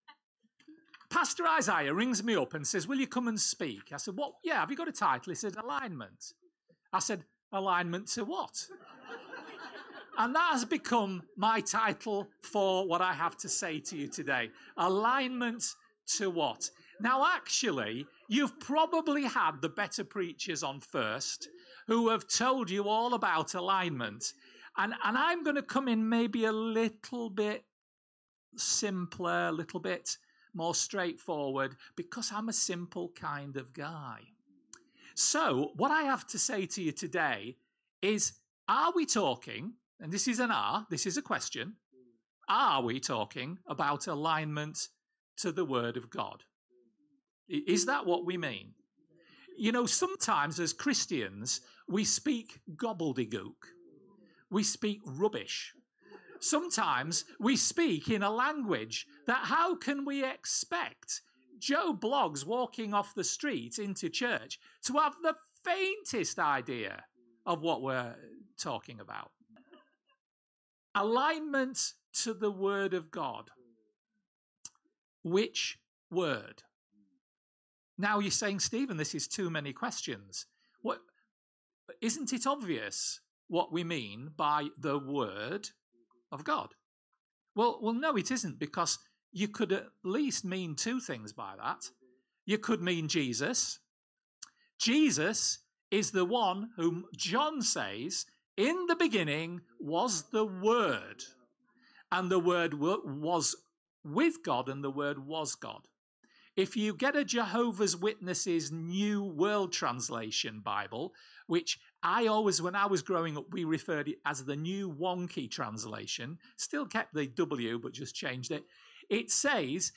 Speaking at the Chosen Church (Leeds) 5th Anniversary weekend